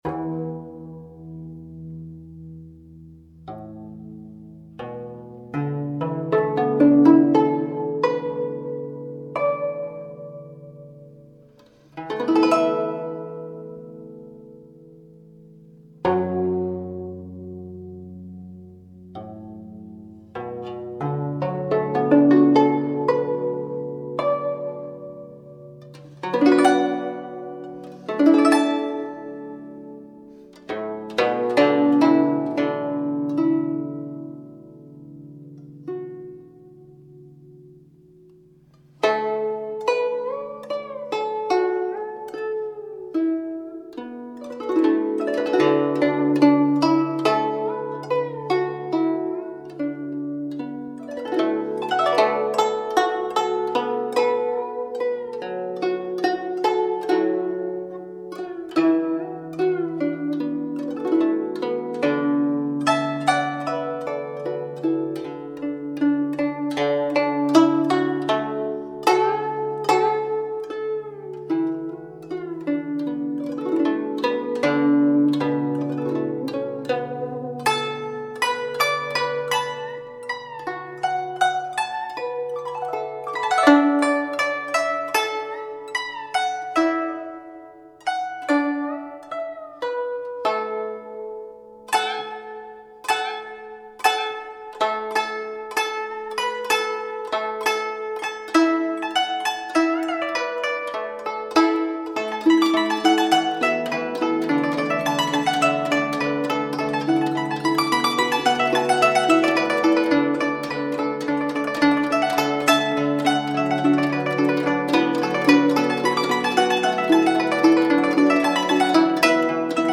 选用不同的民族乐器演绎
长笛飘渺清丽 竖琴轻柔神秘 古筝婉转悠扬 二胡凄美悲情